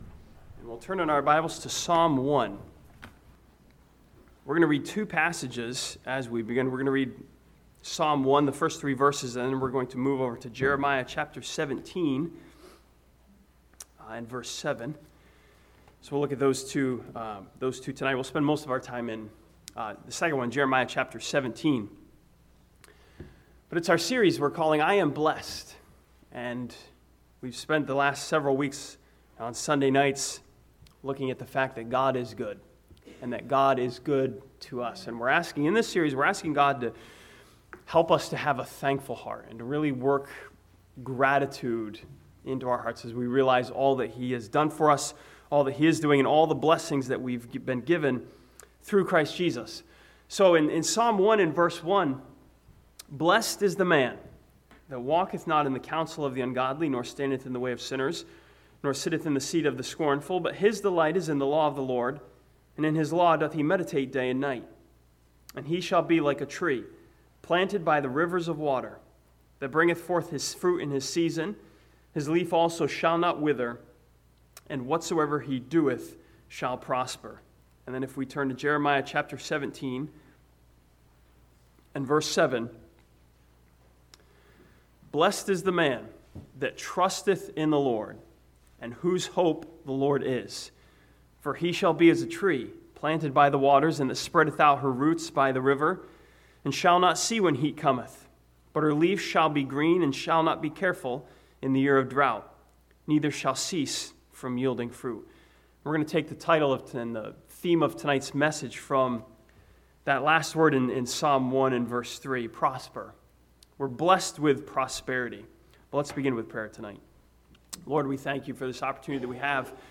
This sermon from Psalm 1 and Jeremiah 17 studies the fact that believers have been blessed with prosperity through Jesus Christ.